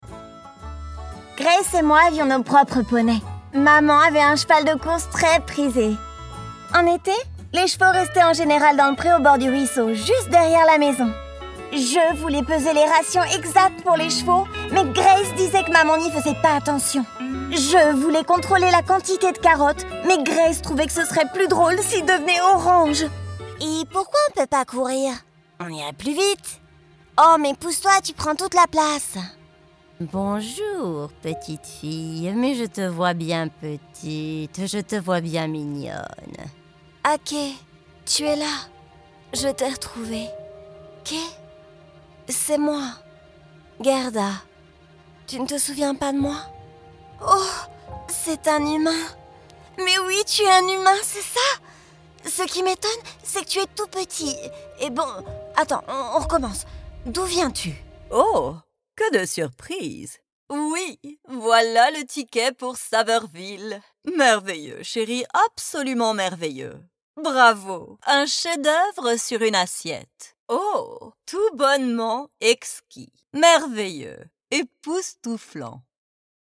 Animation
My voice is flexible and adaptable.
Home Studio Setup
Microphone : RODE NT1-A